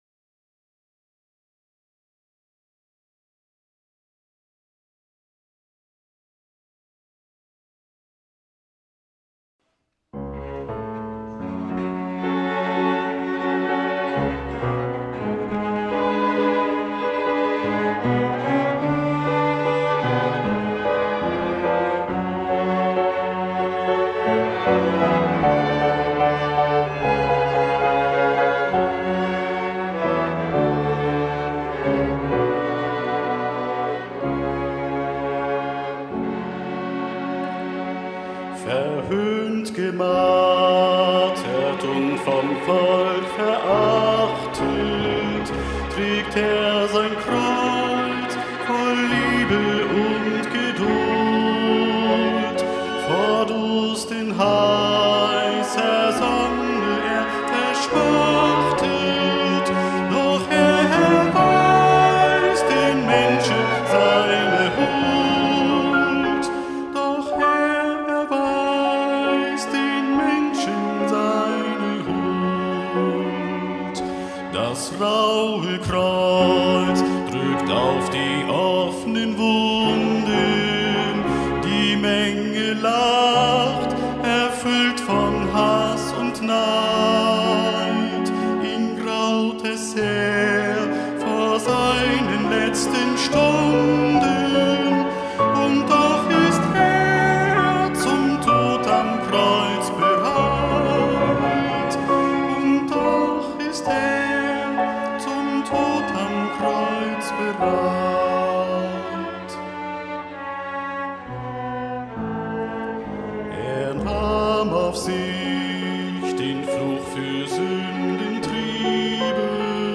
Passionssingen 2026